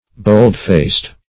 Bold-faced \Bold"-faced`\, a.